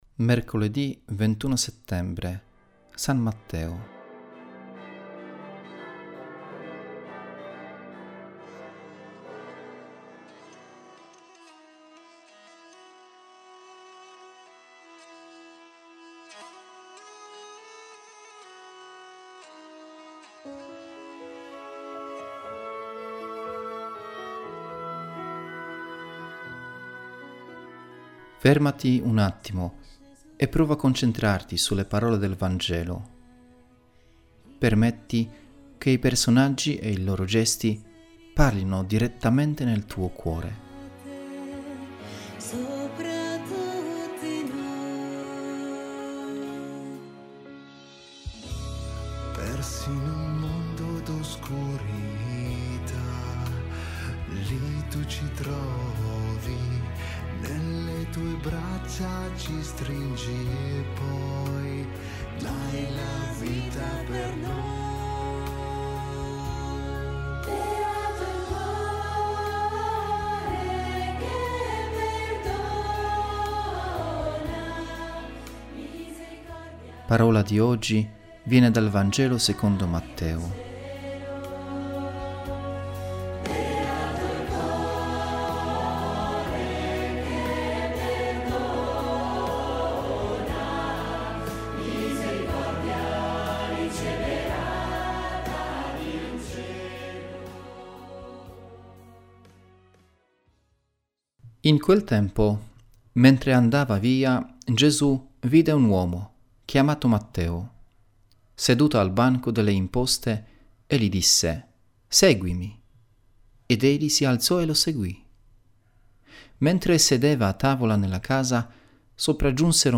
Commento